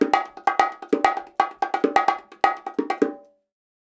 130BONGO 05.wav